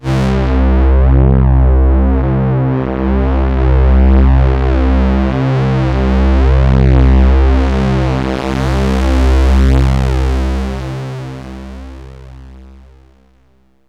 STRINGS 0013.wav